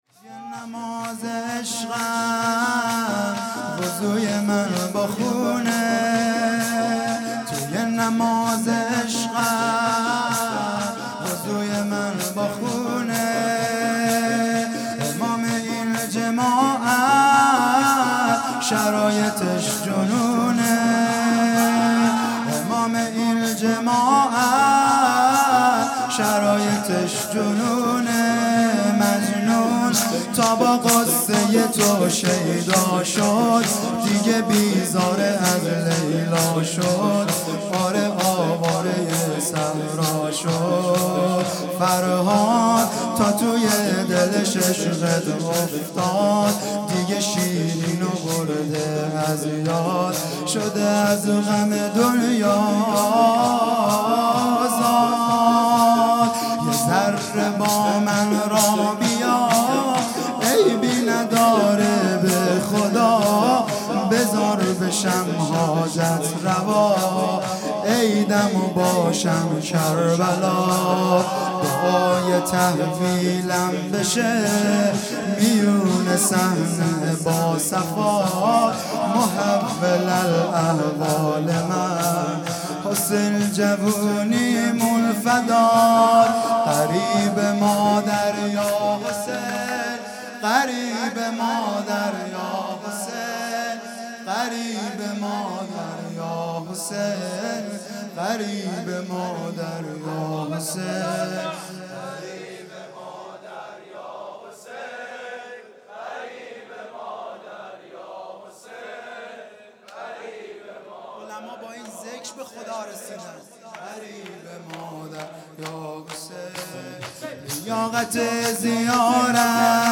هیئت دانشجویی فاطمیون دانشگاه یزد
شور
توی نماز عشقم|شهادت حضرت زهرا (س) ۱۰ اسفند ۹۵